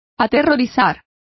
Complete with pronunciation of the translation of terrify.